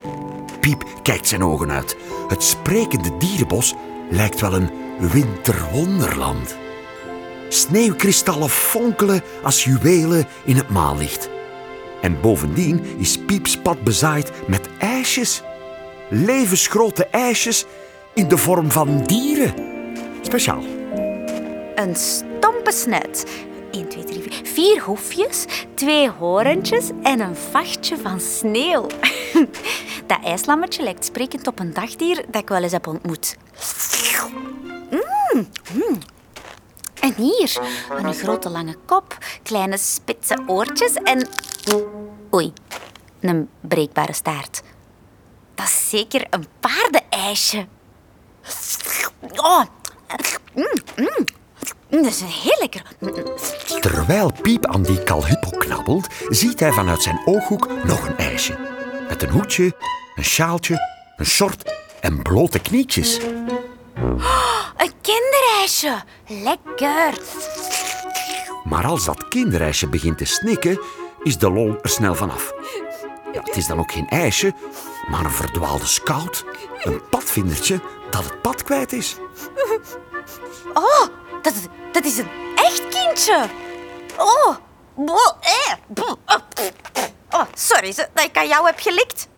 Met de stemmen van Dominique Van Malder, Evelien Bosmans, Liesa Van der Aa, Wim Willaert, Noémie Wolfs, Tom Vermeir en Lien De Graeve.
De rollen worden ingesproken door de béste acteurs en in bijhorend prentenboek staat ook een voorleestekst.